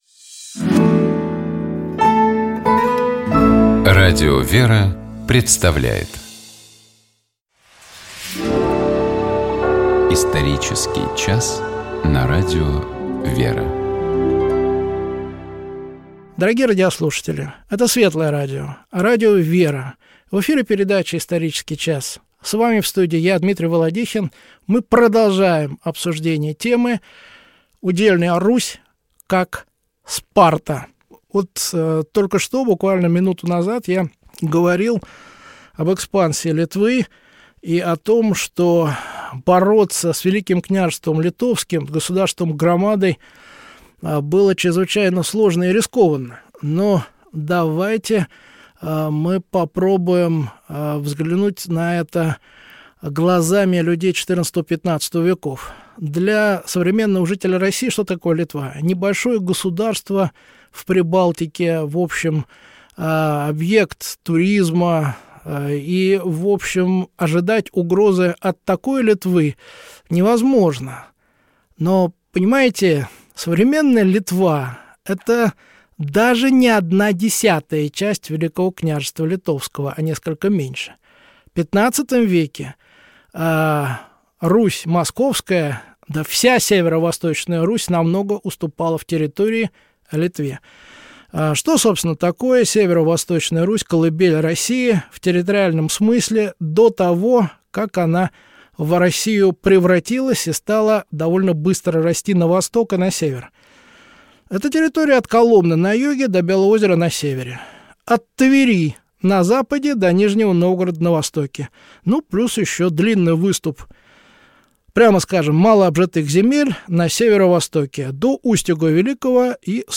Никакого гостя нет, мы с вами наедине и поговорим мы сегодня на тему, которая может показаться странной, необычной, но потерпите минутку, сейчас я объясню ее смысл.